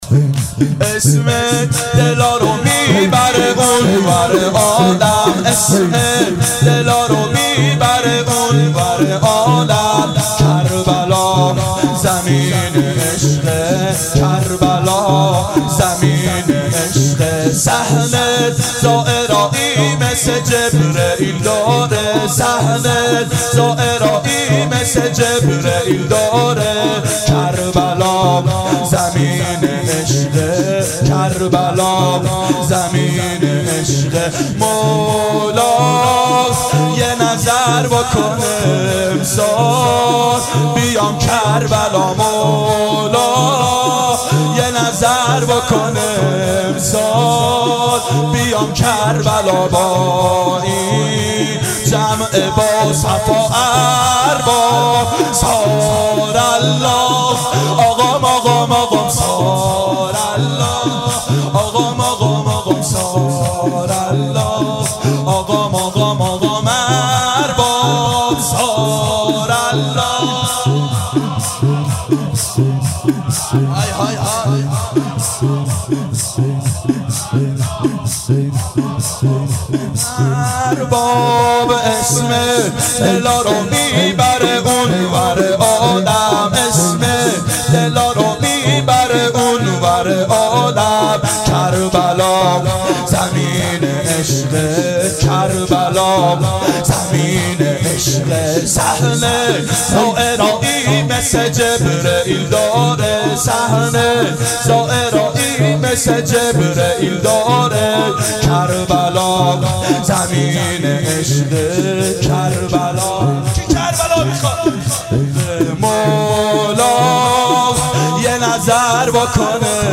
• دهه اول صفر سال 1390 هیئت شیفتگان حضرت رقیه س شب دوم (شب شهادت)